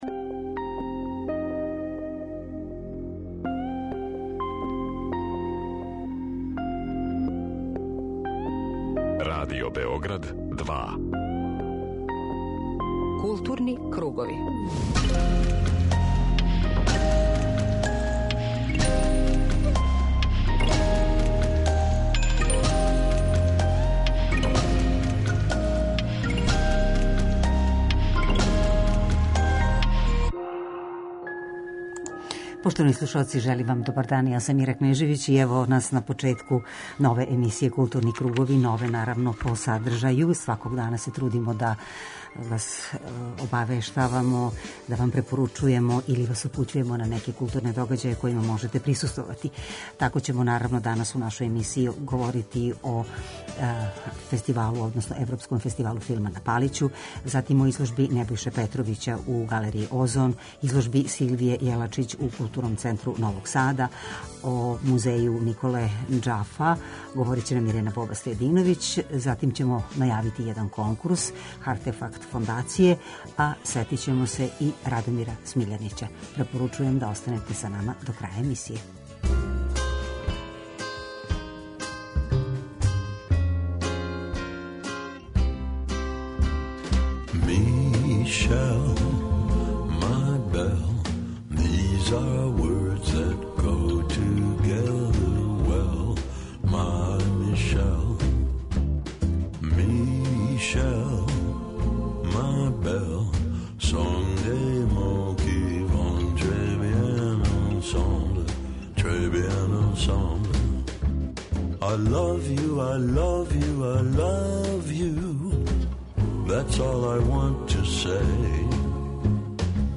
Дневни магазин културе Радио Београда 2